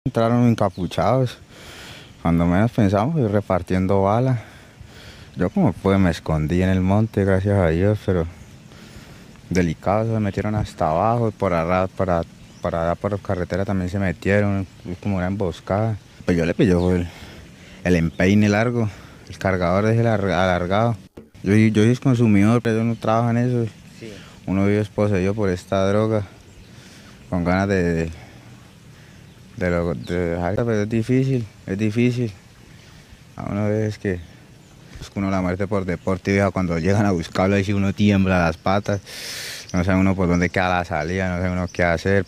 Sobreviviente de la masacre de Floridablanca, Santander